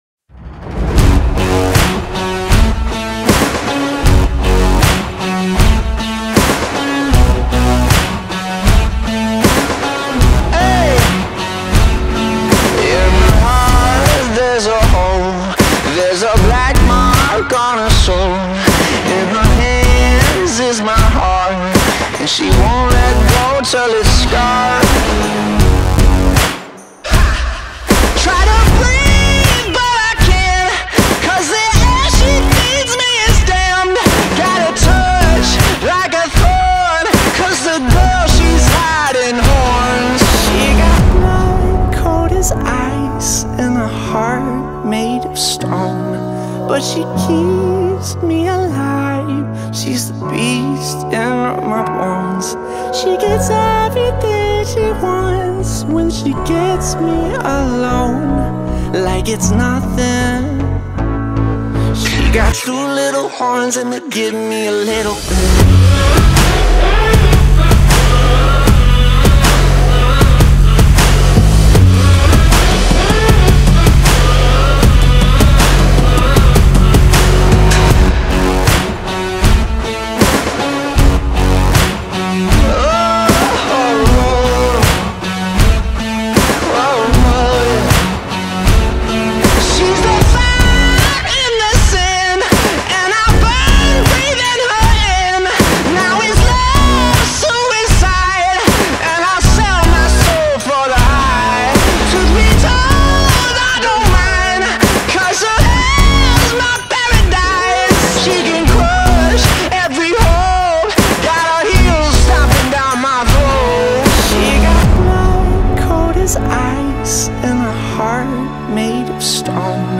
در سبک پاپ راک خونده شده و ملودیش وایب خوبی داره